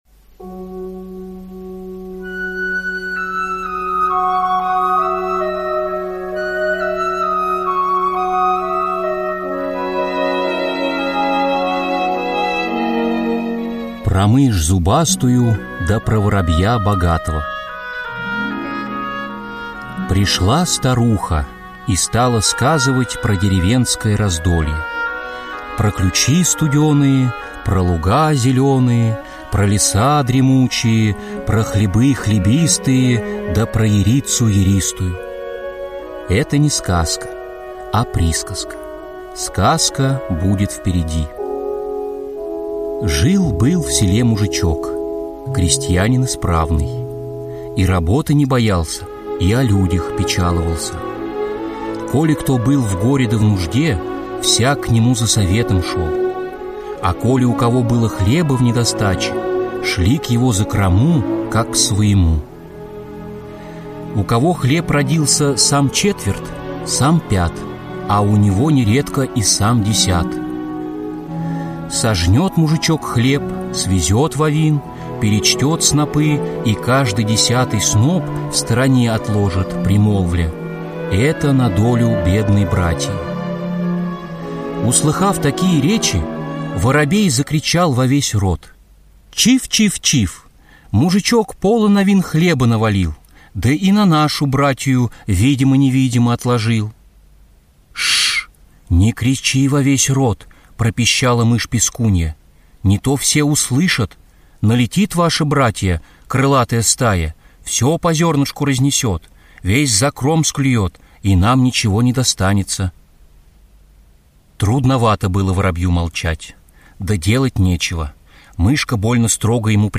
На данной странице вы можете слушать онлайн бесплатно и скачать аудиокнигу "Про мышь зубастую да про воробья богатого" писателя Владимир Даль.